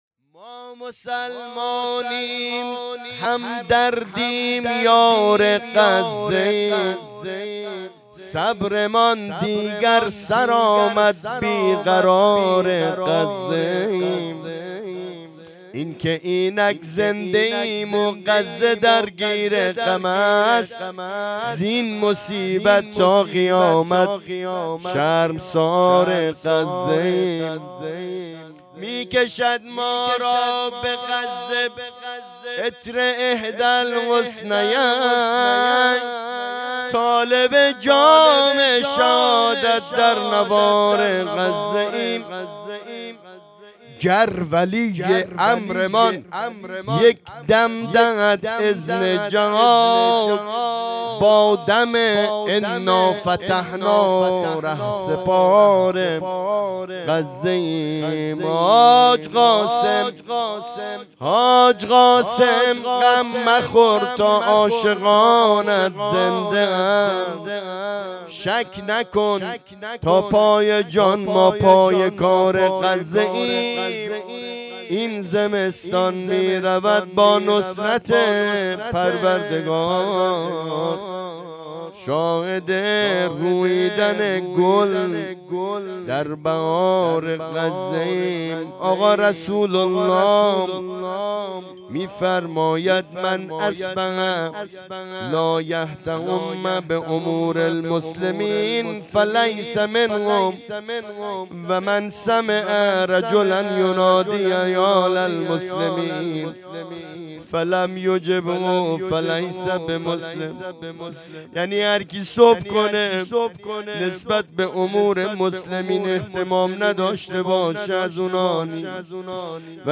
هیئت رایة الزهرا(سلام الله علیها)یزد